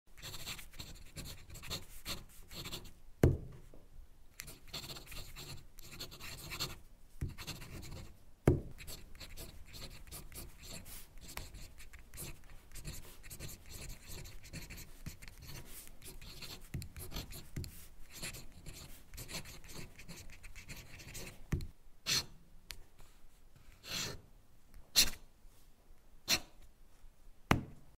Звуки писания пером
Письмо старинным пером